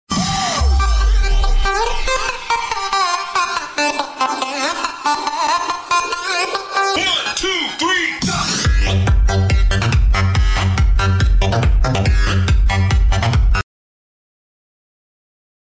Soundtest Harmankardon Onyx Studio 7 sound effects free download